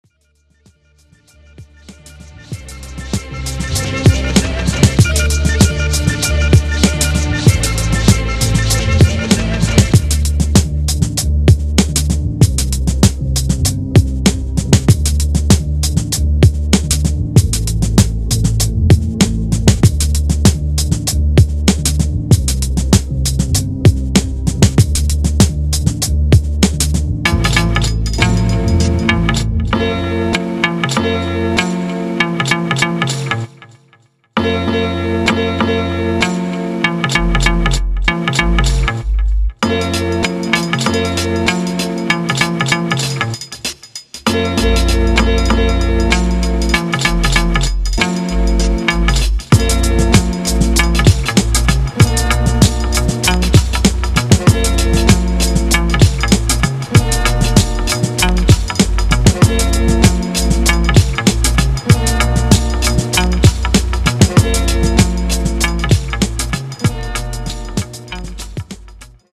Somber music with bright summer undertones.